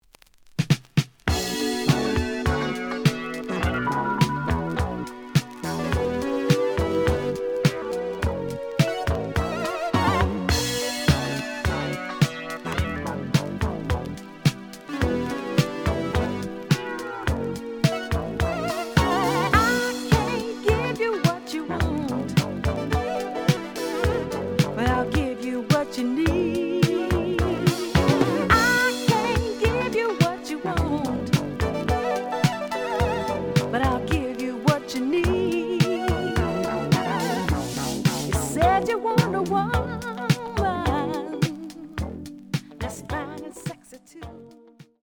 試聴は実際のレコードから録音しています。
The audio sample is recorded from the actual item.
●Genre: Soul, 80's / 90's Soul